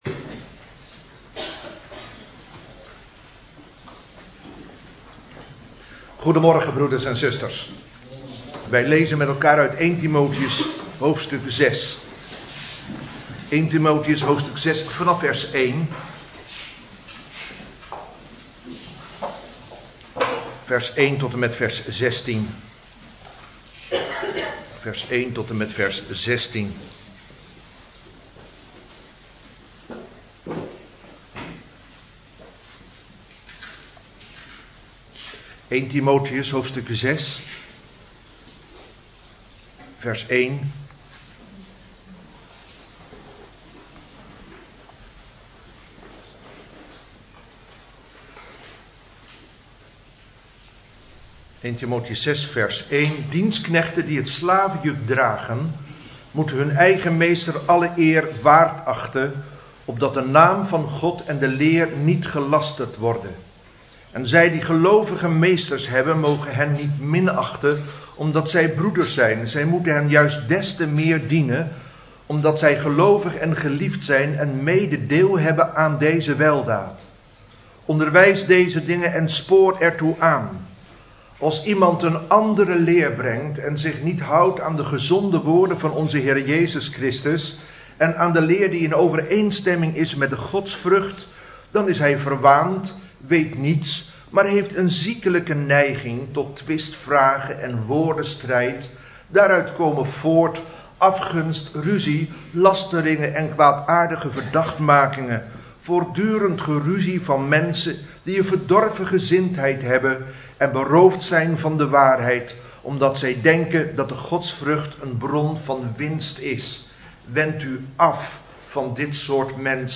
De mens Gods (inzegening oudsten) – Reformatorische Baptistengemeente Heuvelrug